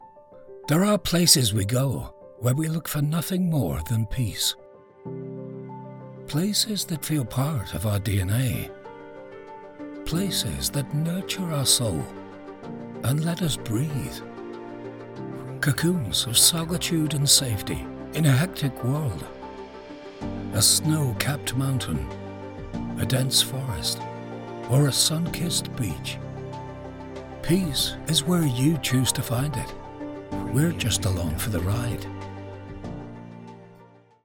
Character Voice Liam Neeson
Rich resonant and engaging voice with the ability to add impersonations such as Russell Crowe, Sean Connery, Ewan Macgregor, John Hurt, Ray Winstone and many more.